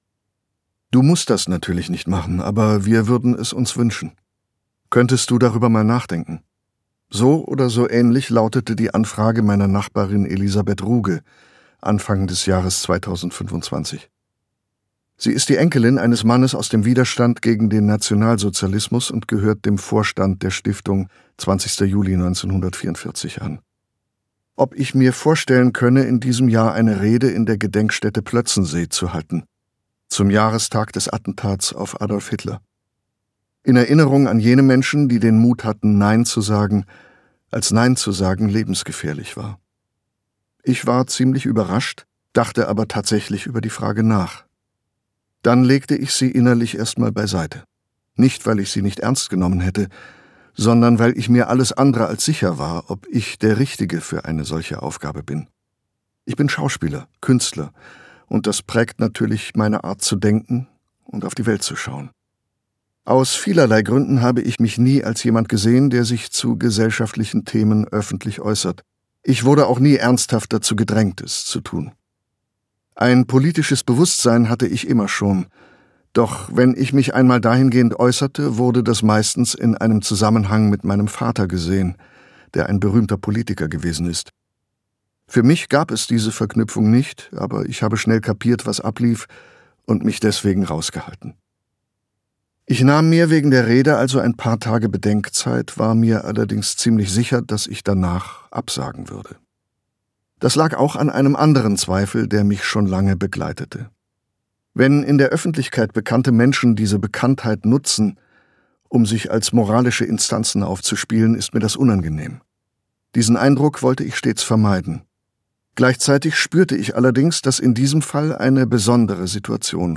Gekürzt Autorisierte, d.h. von Autor:innen und / oder Verlagen freigegebene, bearbeitete Fassung.
Nein sagen Gelesen von: Matthias Brandt